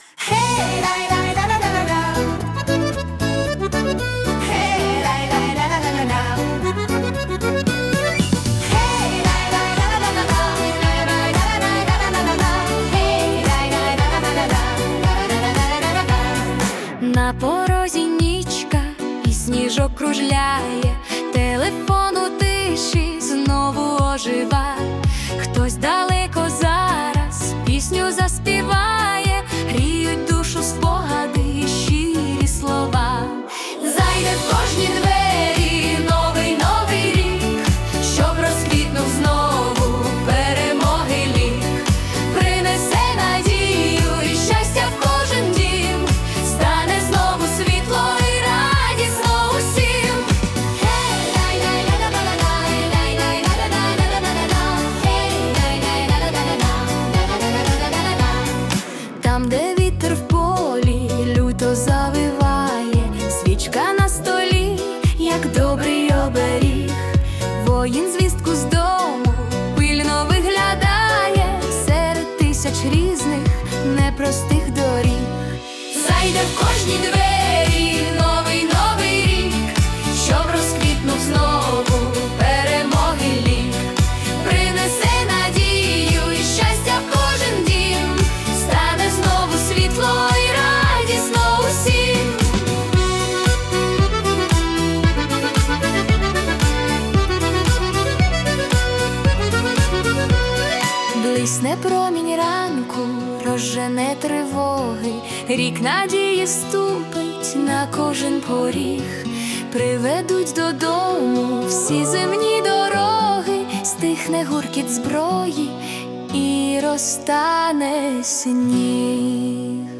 🎵 Жанр: Новорічний поп / Естрада